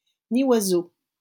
Nyoiseau (French pronunciation: [niwazo]